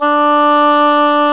WND OBOE3 D4.wav